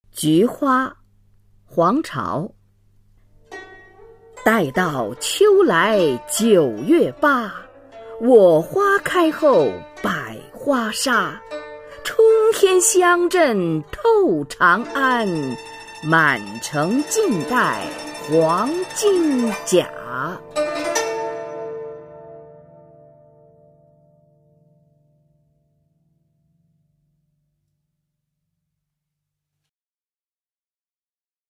[隋唐诗词诵读]黄巢-菊花（待到秋来九月八） 古诗文诵读